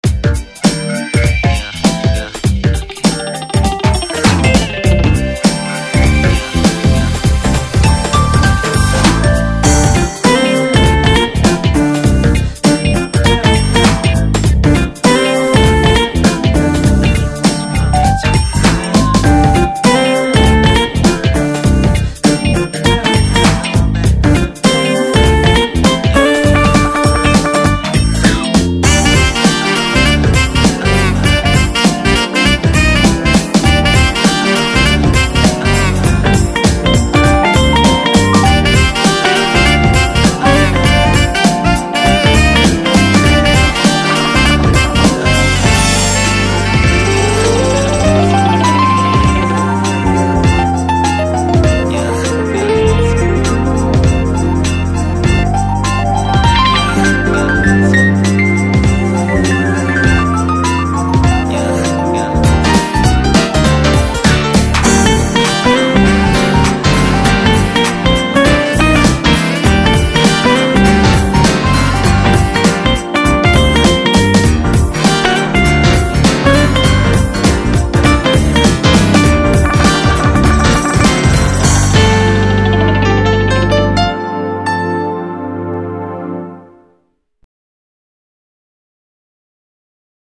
짧지만 잔잔한 펑키(?) 의 퓨젼 부드러운 멜로디가 인상적입니다.